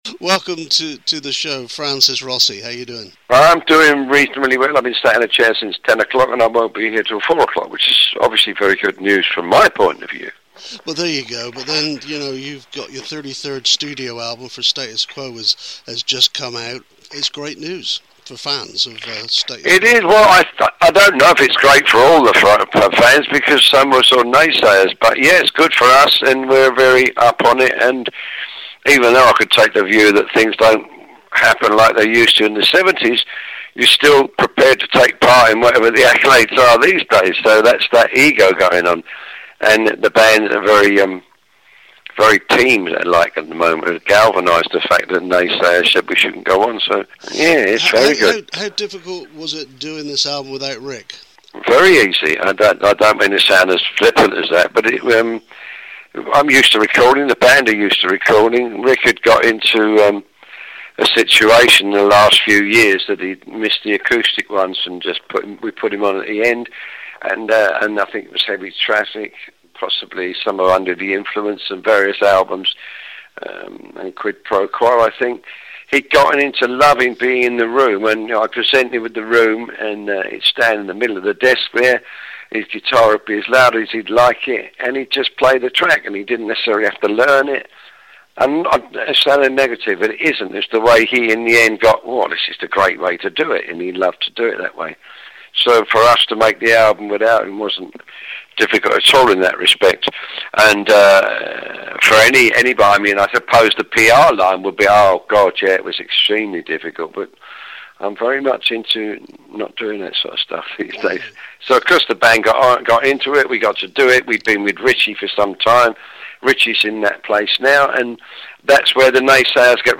via a telephone link.